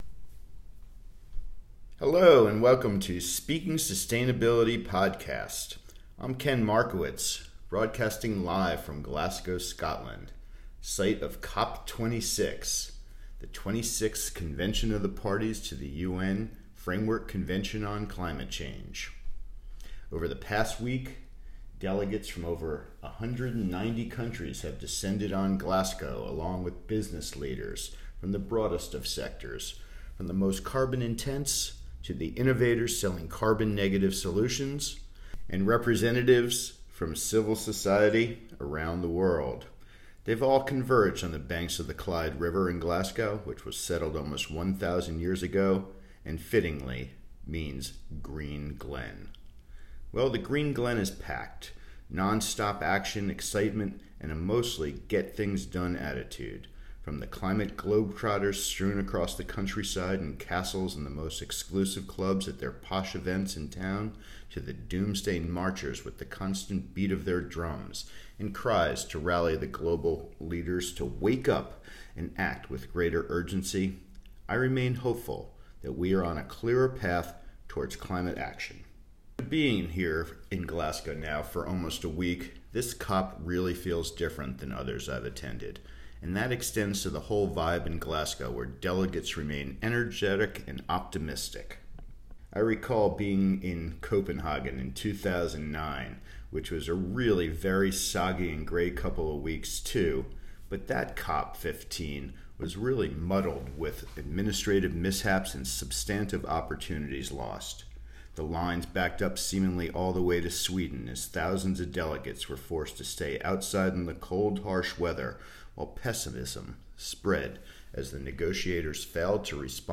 this short audio essay on the Climate Change Conference, placing it in historical context, examining what sets this conference apart, setting out its themes and players, and identifying the challenges and breakthroughs.